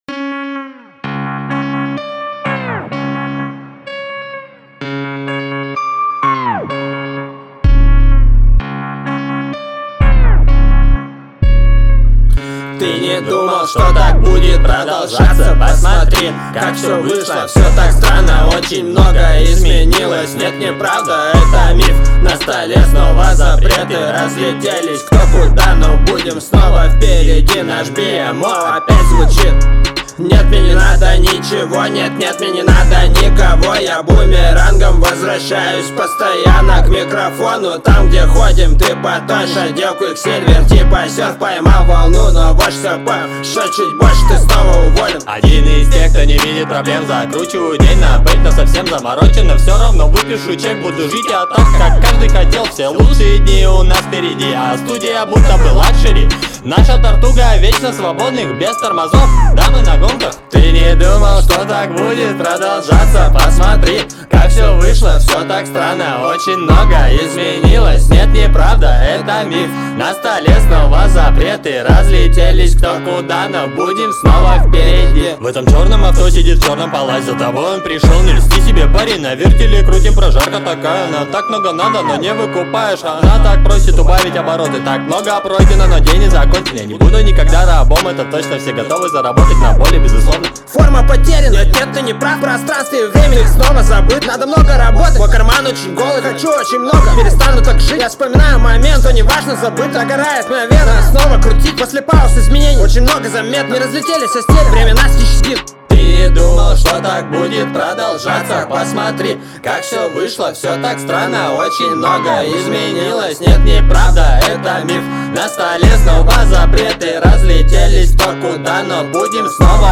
Хотим критике по работе Сведение,мастеринг,подача и т.д (жанр Rap)